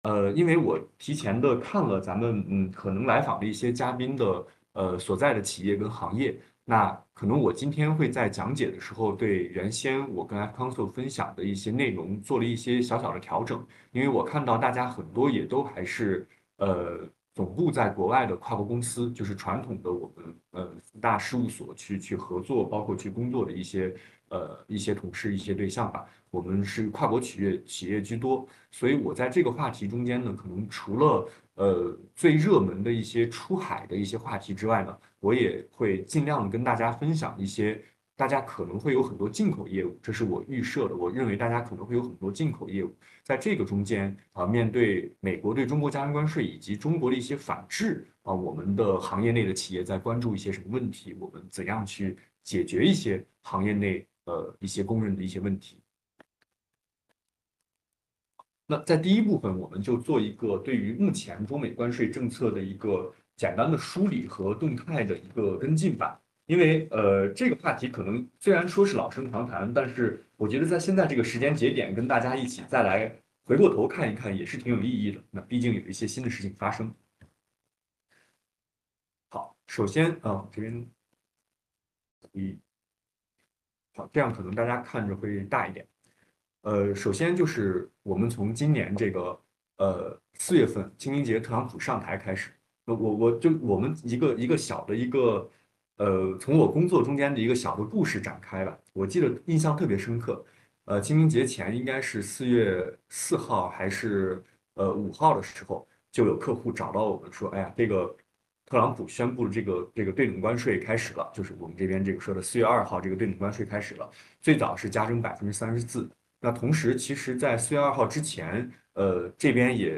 视频会议
活动议程： 1 4： 00 主持人开场 1 4：03 正式开始分享 一、中美关税政策梳理与动态 1、中美关税政策脉络与核心内容 Ø 2025年美国加征关税及中国反制关税措施的政策内容、关键节点、税率调整变化。
互动问答